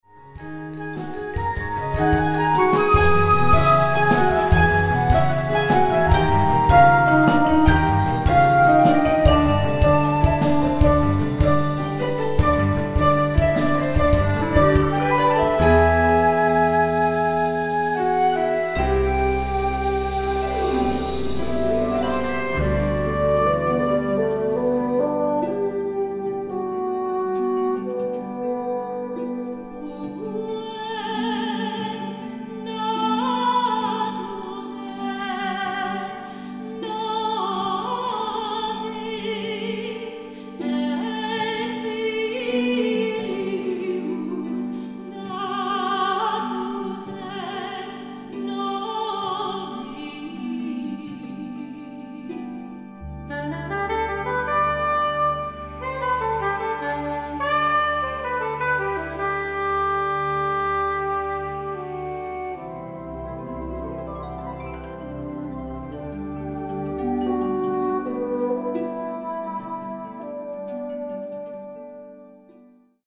dove la Musica fonde sapori classici, pop e rock progressive
L'interagire di vari stili e generi col "canto dell'Anima".